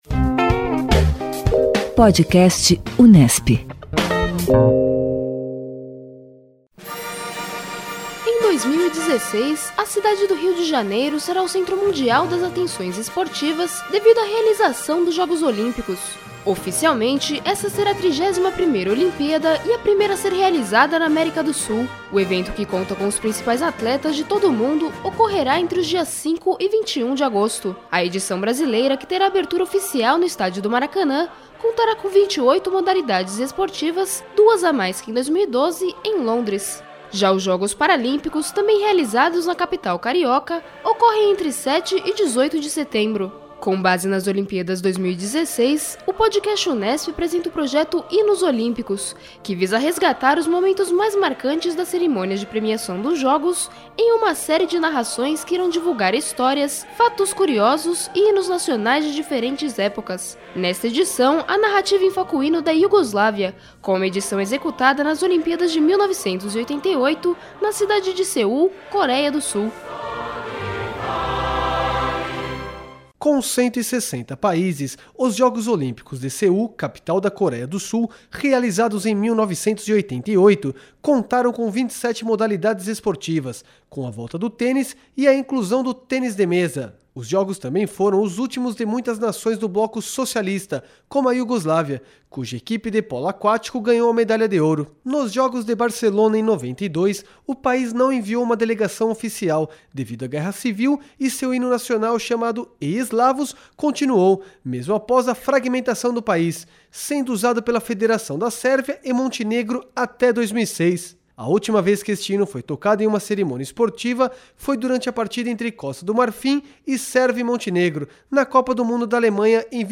Nesta edição, o Podcast Unesp apresenta o Hino da Iugoslávia, com uma edição executada nas Olimpíadas de 1988 na cidade de Seul, Coréia do Sul.